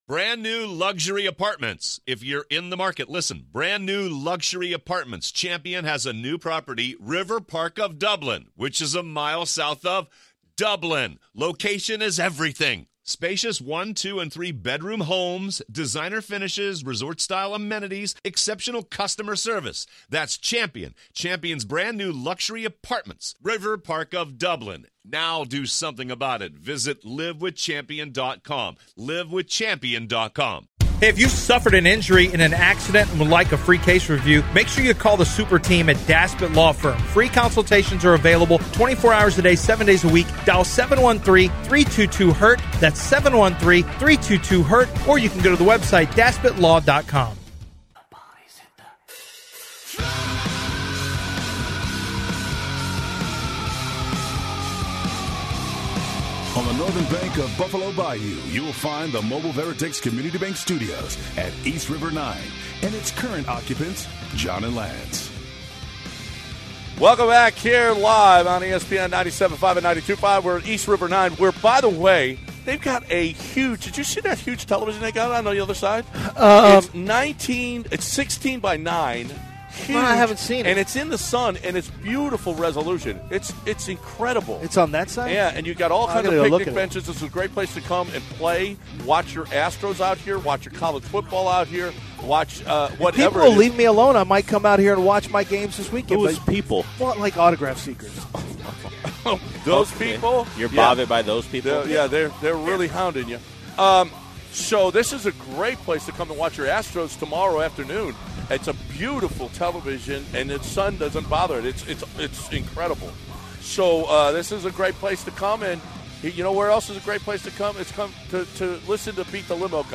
Live from East River 9